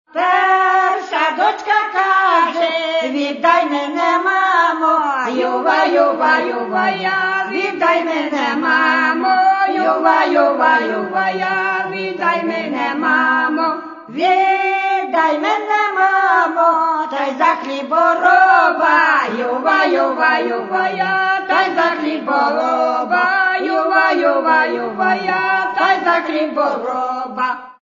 Каталог -> Народная -> Аутентичное исполнение